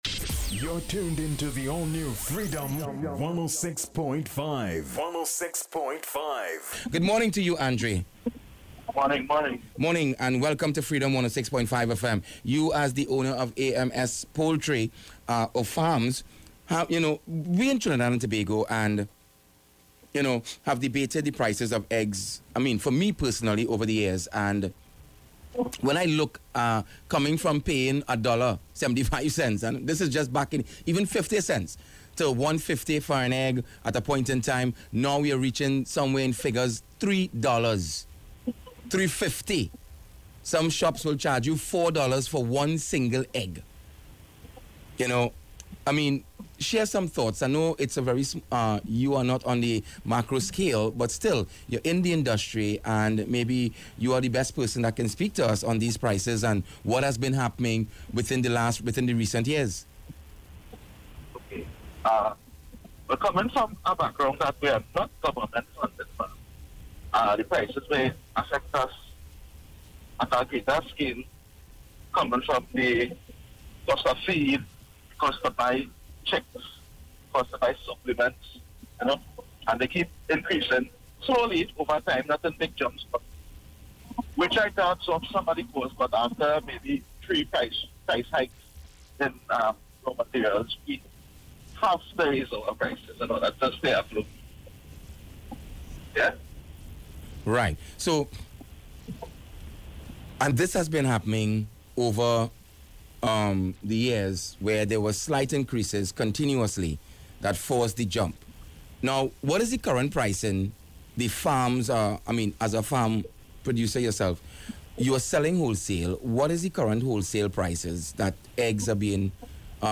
DEBATE ON EGG AND POULTRY PRICES - Freedom 106.5 FM